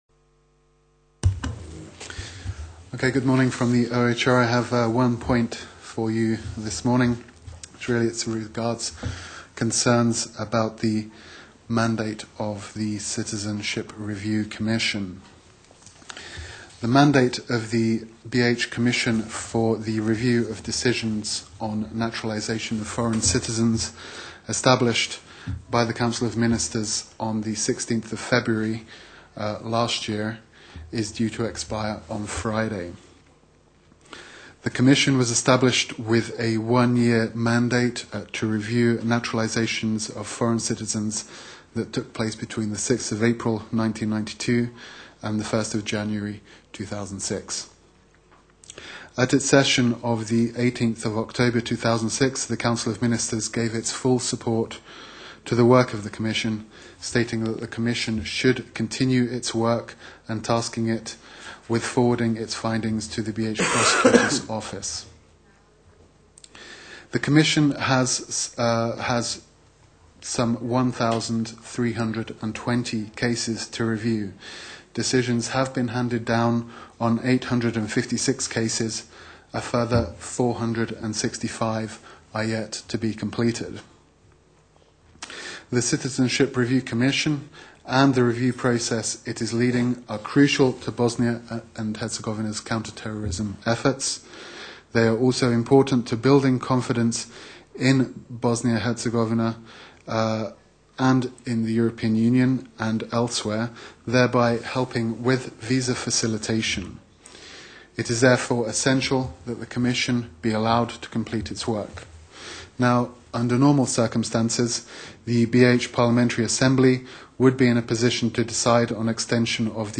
Transcript of the International Agencies’ Joint Press Conference